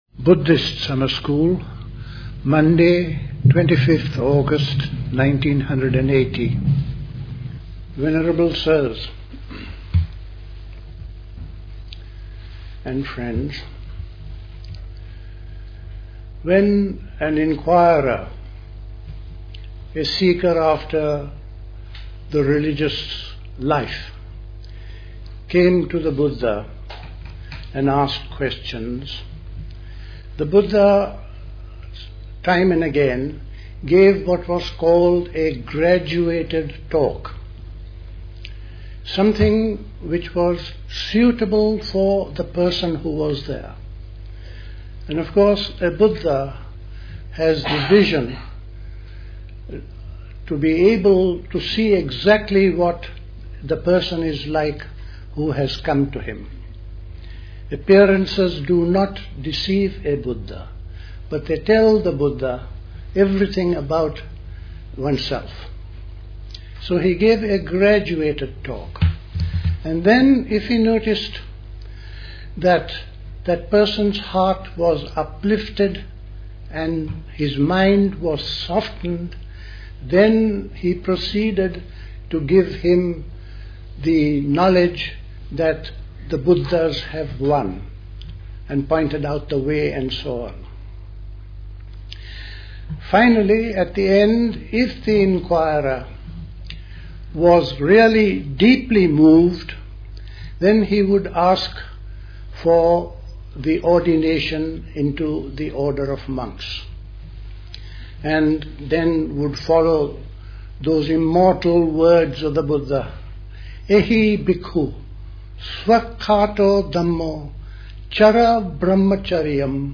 at High Leigh Conference Centre, Hoddesdon, Hertfordshire on 25th August 1980